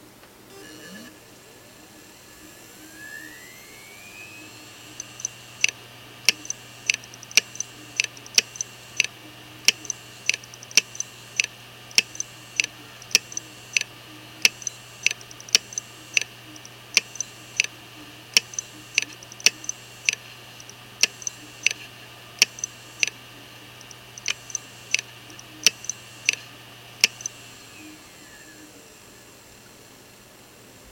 (483.98 KB, HDD.mp3)